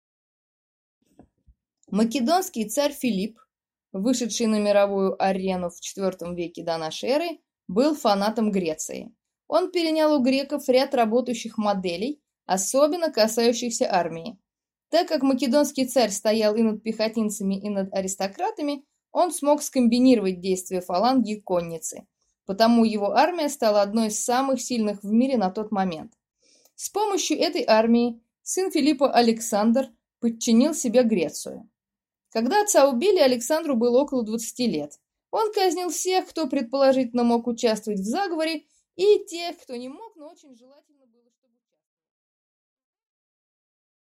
Аудиокнига Македония. Эллинизм | Библиотека аудиокниг